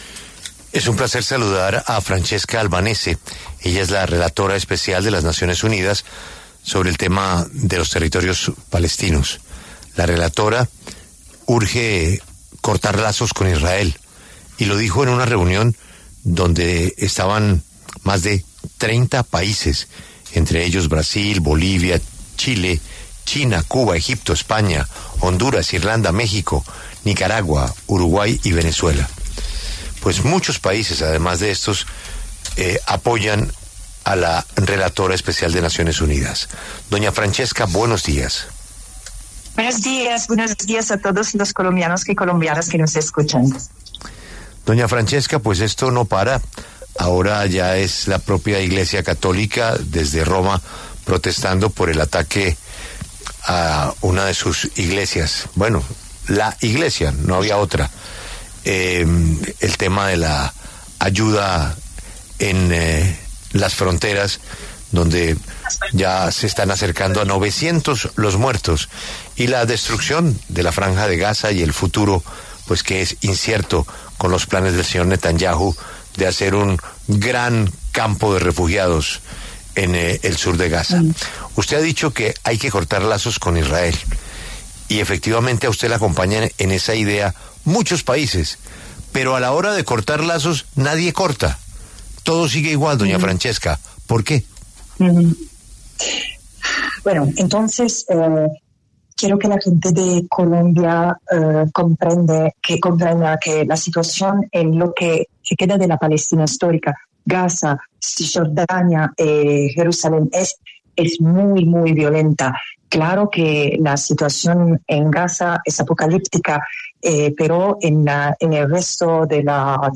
Francesca Albanese, relatora especial de las Naciones Unidas sobre los territoriospalestinos, conversó en La W sobre el actuar de la comunidad internacional sobre la guerra en Gaza.